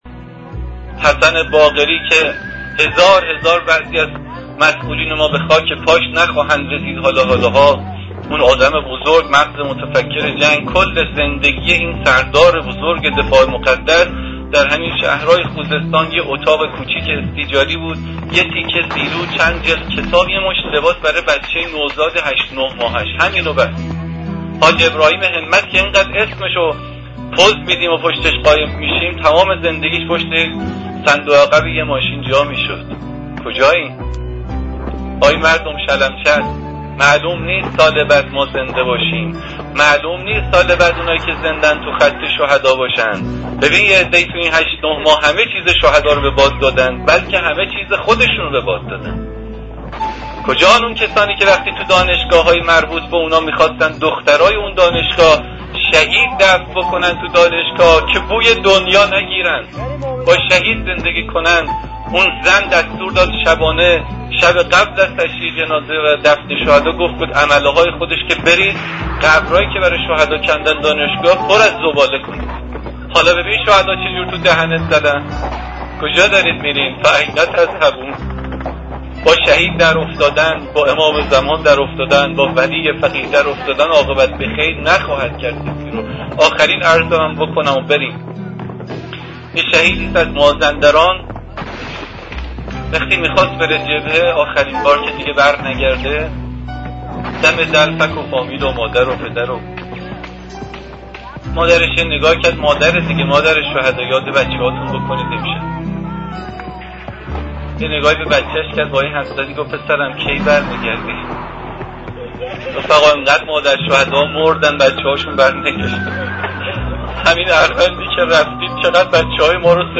صوت روایتگری